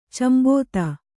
♪ cambon